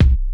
Kick _11.wav